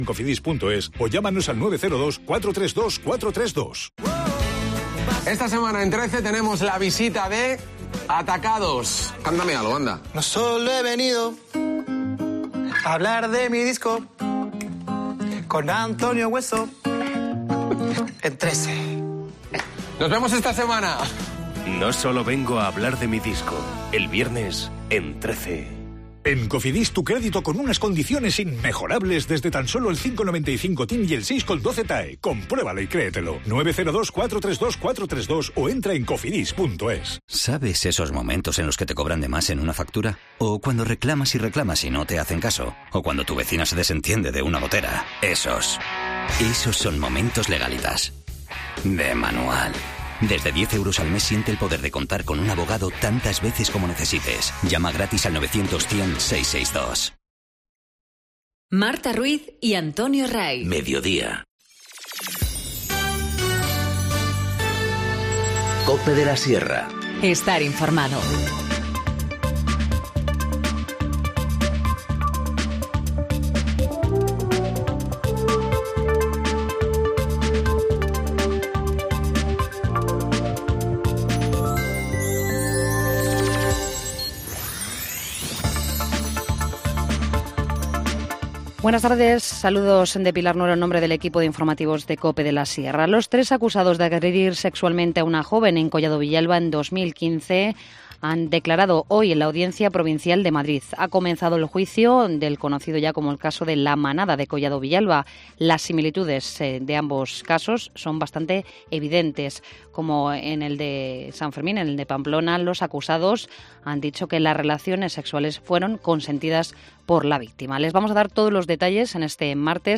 Informativo Mediodía 15 enero- 14:20h